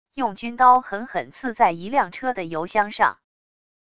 用军刀狠狠刺在一辆车的油箱上wav音频